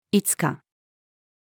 五日-female.mp3